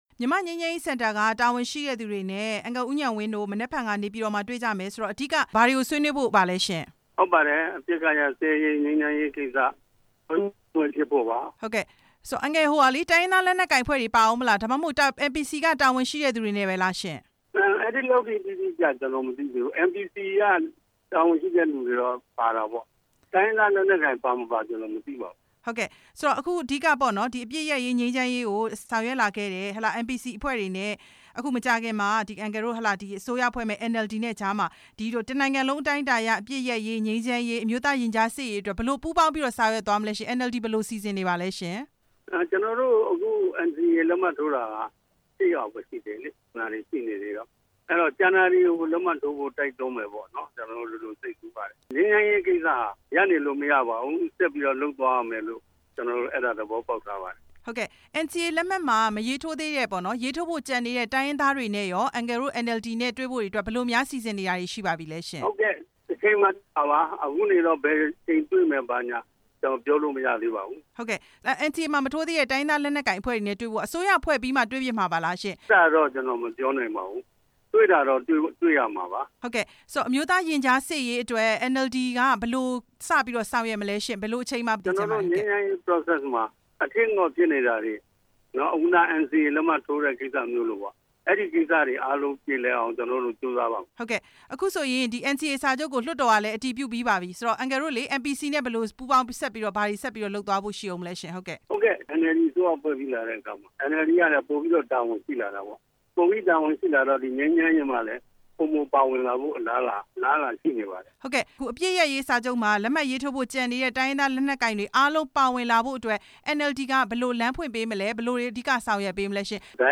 မေးမြန်းချက်